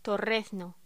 Locución: Torrezno